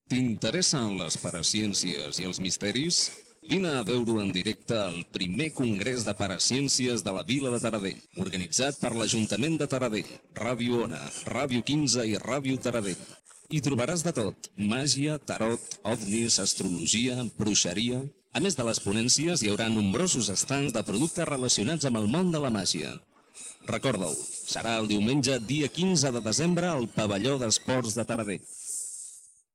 Promoció del primer Congrés de Paraciències a Taradell